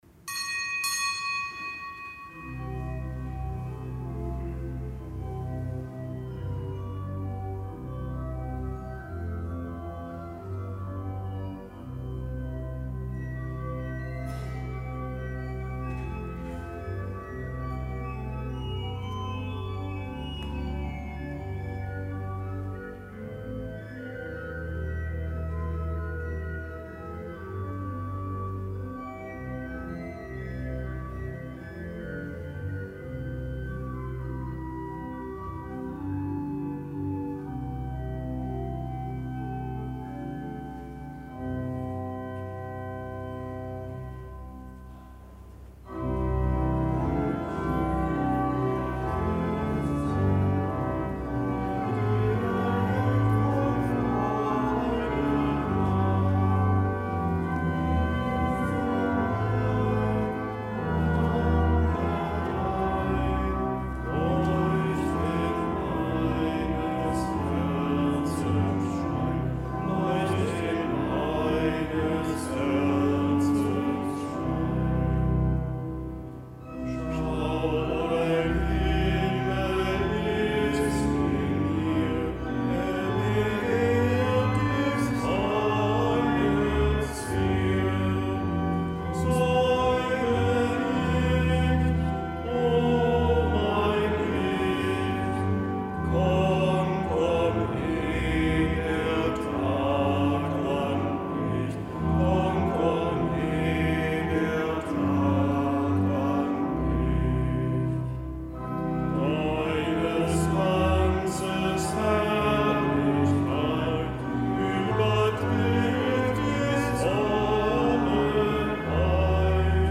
Kapitelsmesse aus dem Kölner Dom am Samstag der dritten Adventswoche.